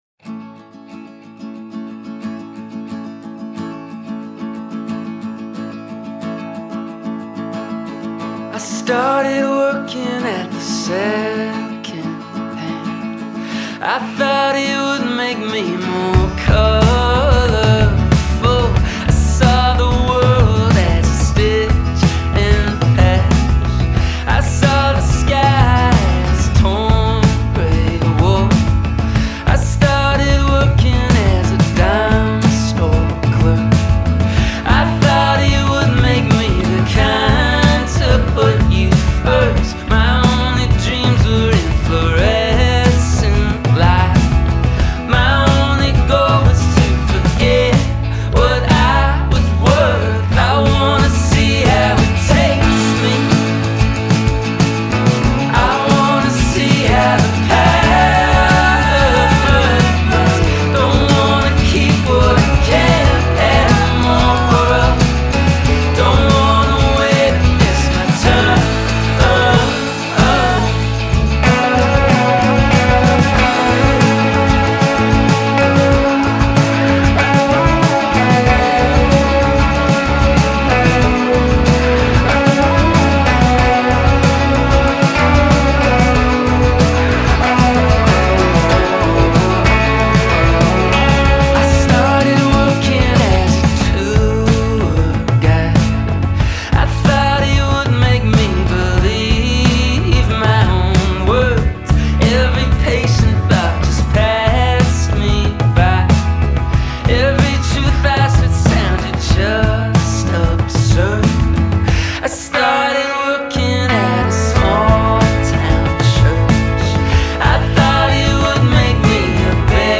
Subdued, melancholic, and beautiful.